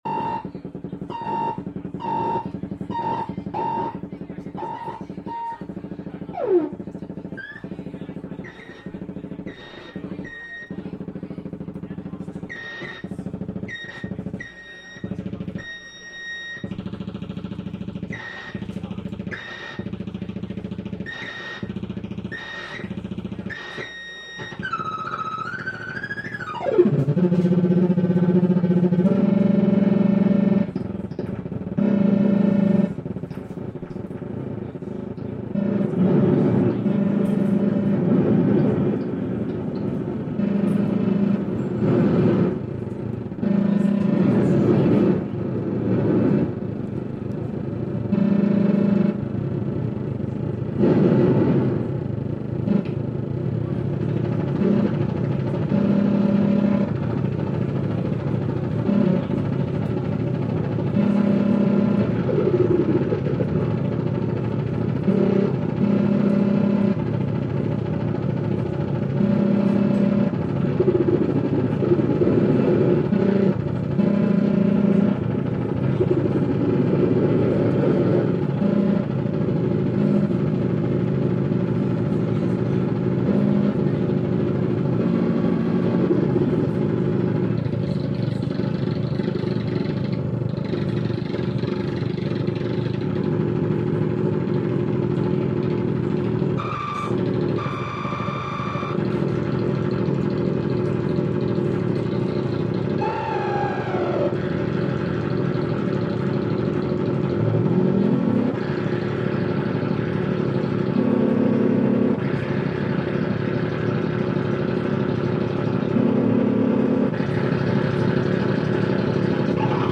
noise/experimental band